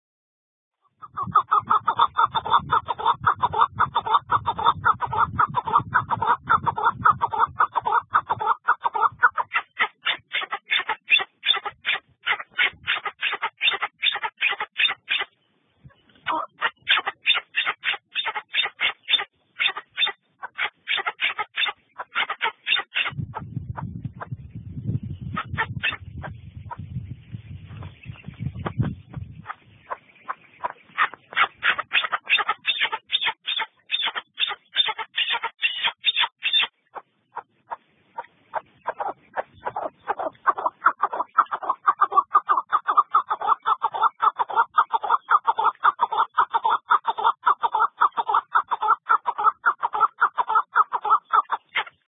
دانلود آهنگ کبک از افکت صوتی انسان و موجودات زنده
جلوه های صوتی
دانلود صدای کبک از ساعد نیوز با لینک مستقیم و کیفیت بالا